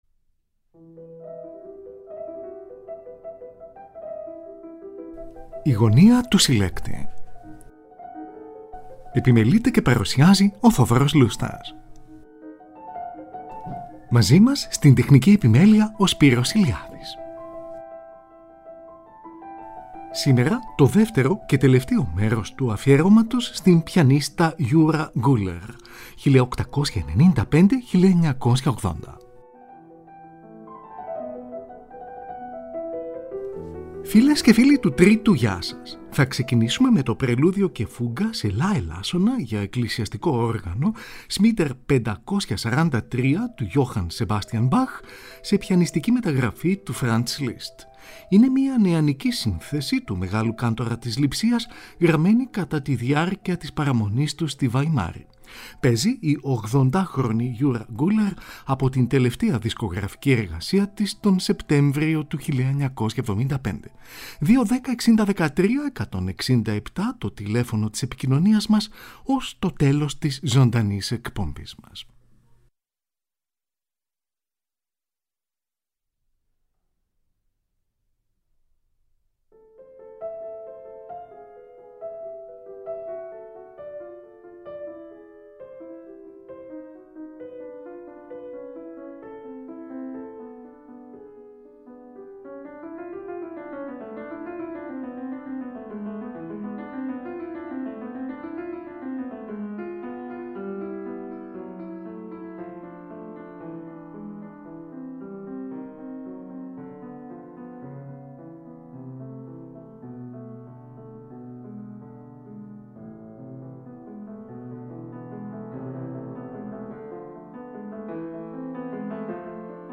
Ερμηνεύει έργα των François Couperin του Μεγάλου, Jean-Philippe Rameau, Johann Sebastian Bach, Ludwig van Beethoven και Frédéric Chopin.